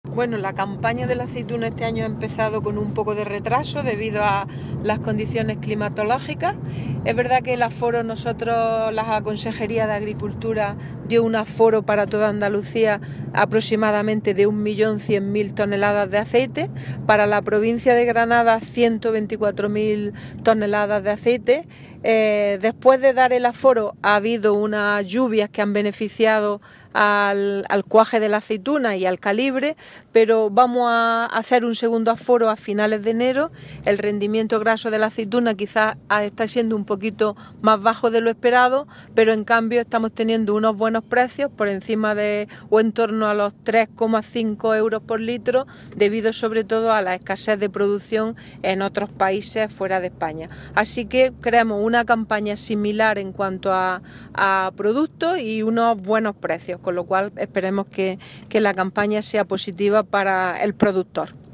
Declaraciones de Carmen Ortiz sobre la campaña de aceite